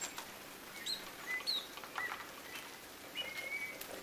erikoista kuusitiaisen ääntä